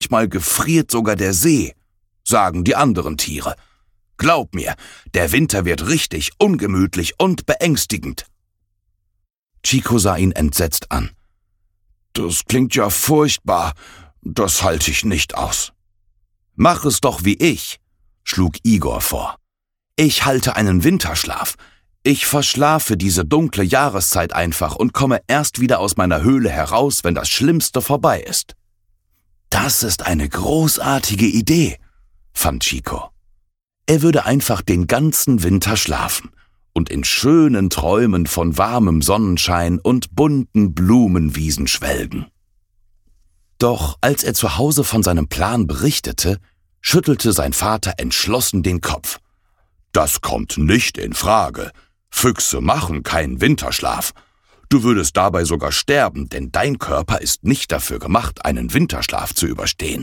Über Hoffnung, Traurigsein und Trost - Hörbuch